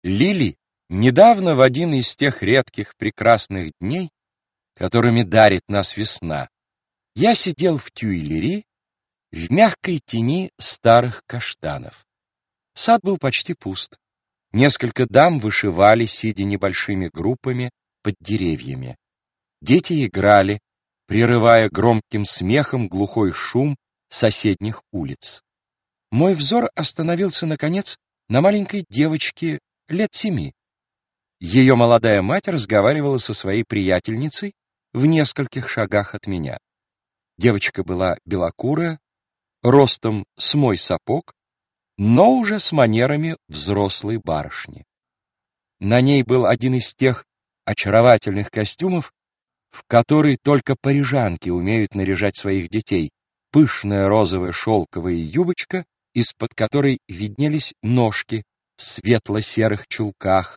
Аудиокнига Новые сказки Нинон | Библиотека аудиокниг